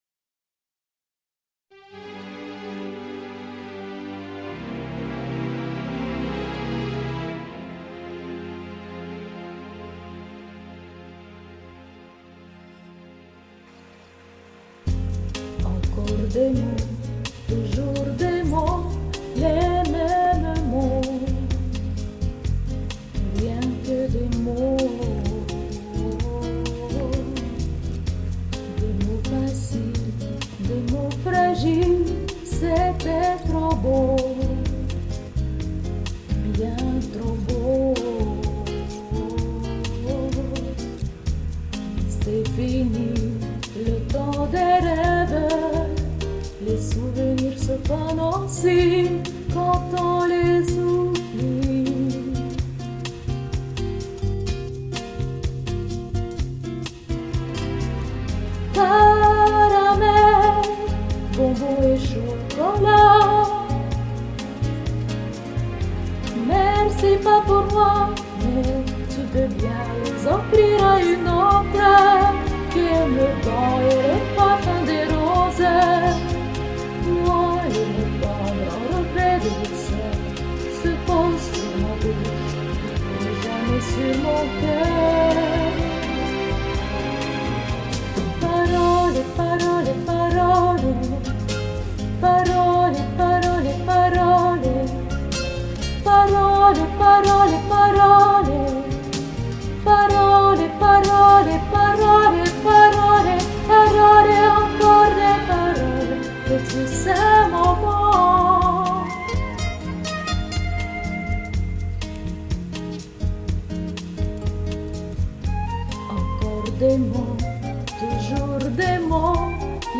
Вот ведь силища голоса!